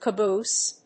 /kəbúːs(米国英語), kʌˈbu:s(英国英語)/
caboose.mp3